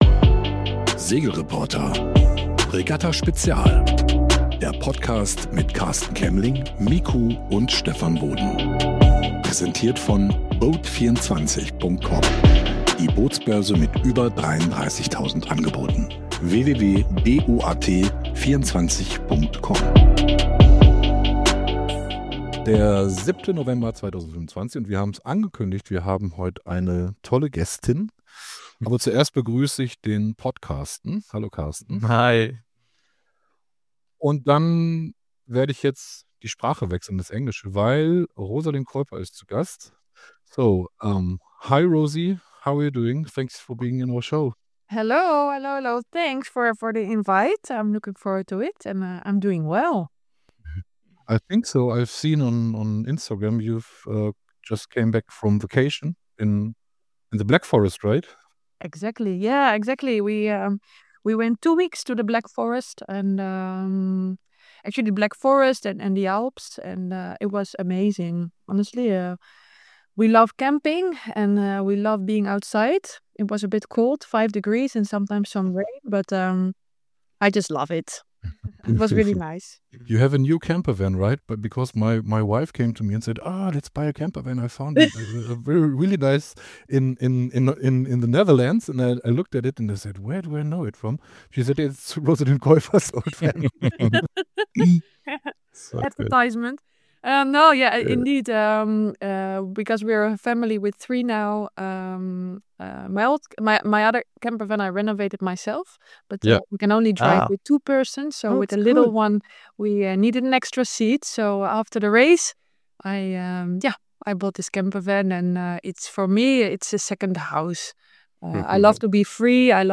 Interview
Wir hatten sie im SR-Podcast zu Gast und mit ihr ausgiebig gesprochen. Was sind ihre Pläne?